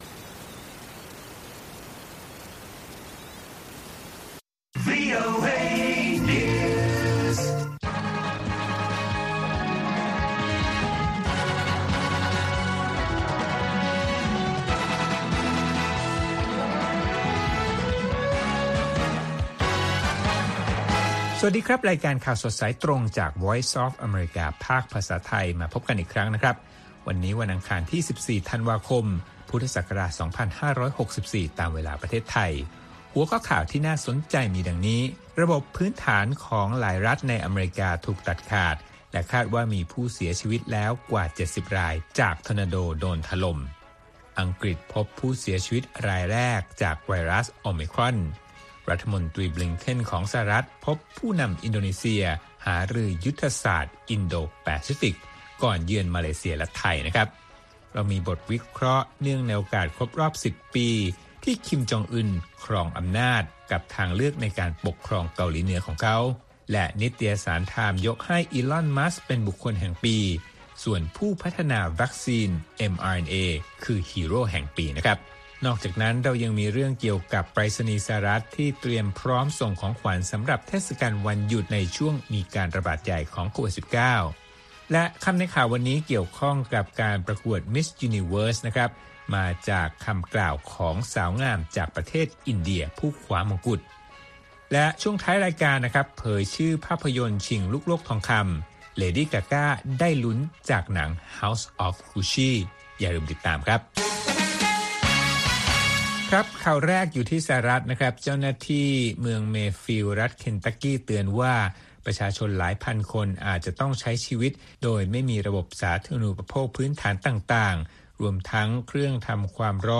ข่าวสดสายตรงจากวีโอเอ ภาคภาษาไทย ประจำวันอังคารที่ 14 ธันวาคม 2564 ตามเวลาประเทศไทย